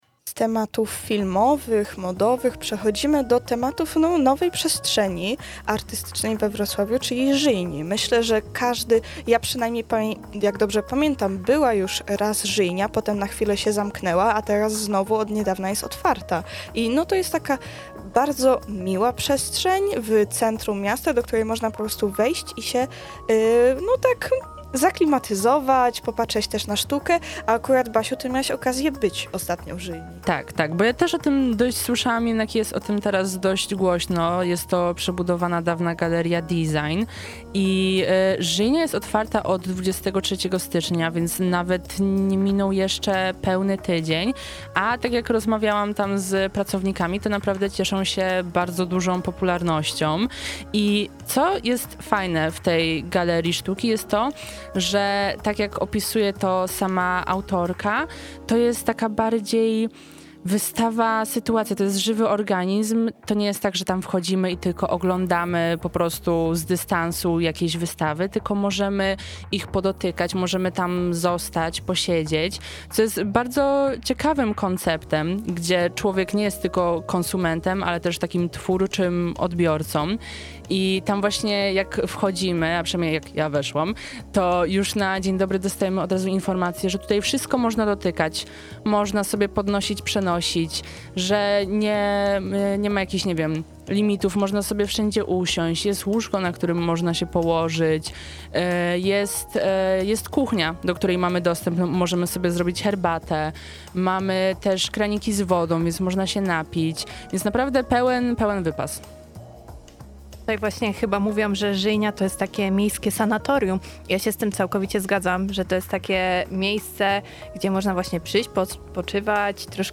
Więcej o tym, jak wygląda Żyjnia i skąd wzięła się jej nazwa, możecie usłyszeć w rozmowie z czwartkowej Pełnej Kultury